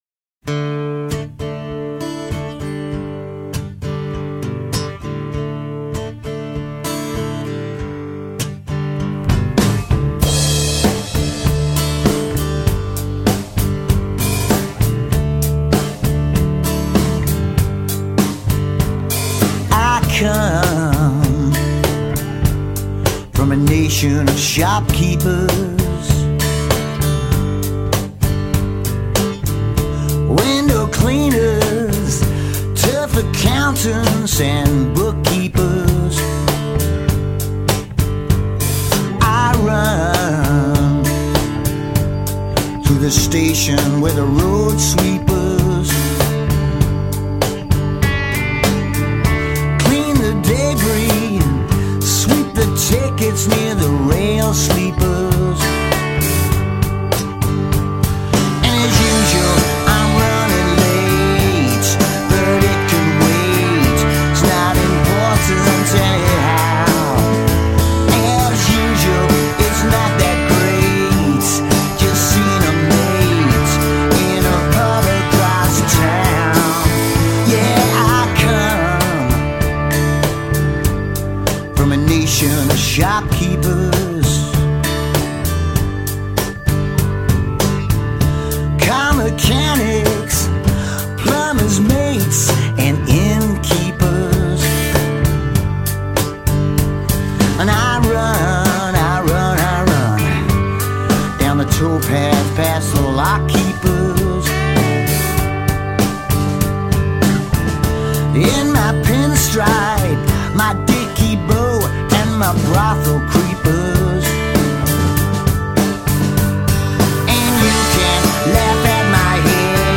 And he’s accompanied by a mando.